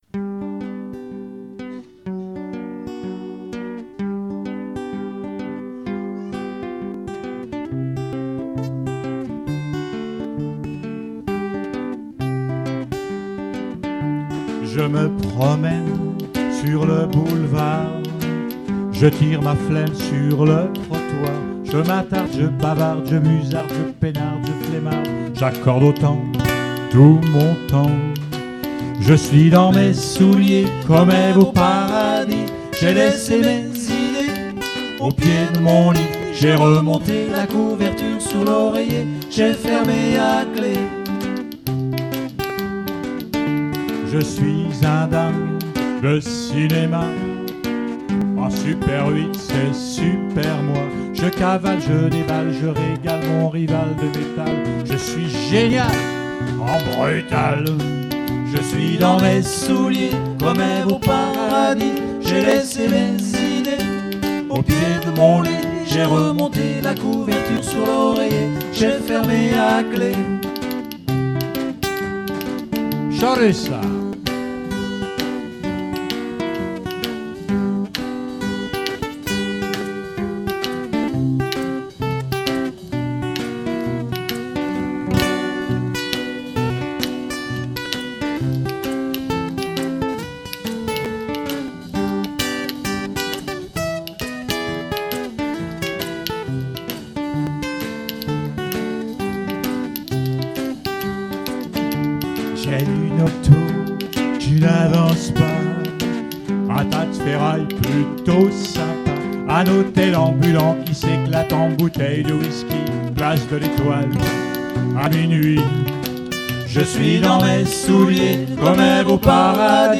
Quelques morceaux ou extraits enregistrés sur scène :